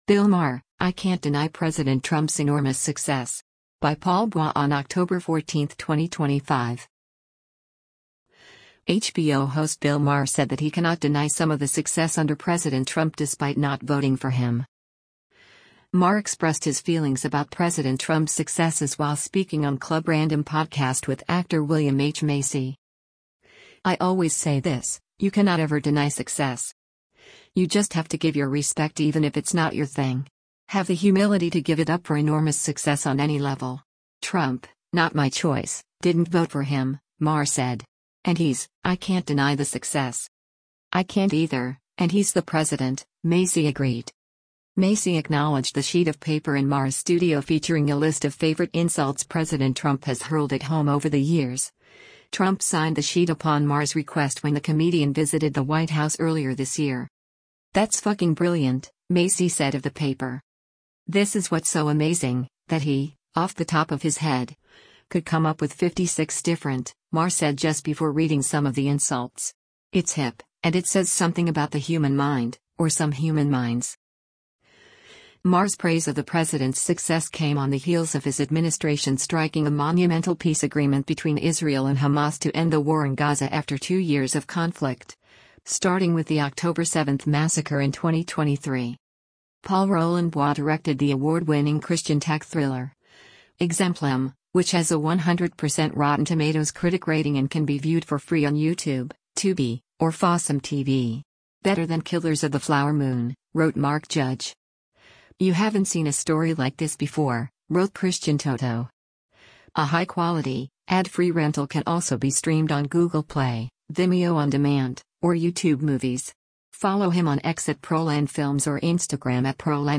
Maher expressed his feelings about President Trump’s successes while speaking on Club Random podcast with actor William H. Macy.